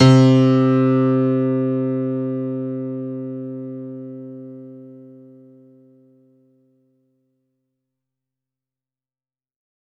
C2  DANCE -L.wav